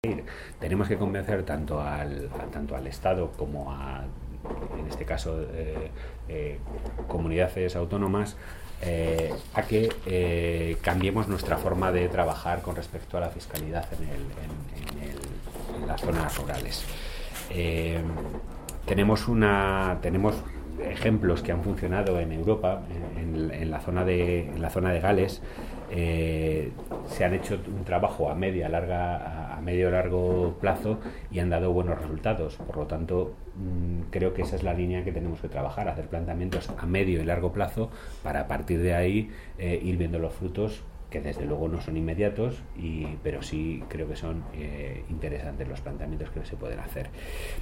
Vicepresidencia Primera Jueves, 22 Noviembre 2018 - 12:00pm El director general de Coordinación y Planificación, Eusebio Robles ha subrayado en el Foro de Despoblación del diario La Tribuna de Albacete, que el Gobierno de Castilla-La Mancha ha reivindicado una fiscalidad adaptadas a las zonas rurales y un modelo de financiación autonómica que mejore a los municipios en comarcas y áreas con despoblación. eusebio_robles-_fiscaliad.mp3 Descargar: Descargar